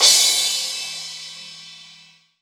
4SA CYMB.wav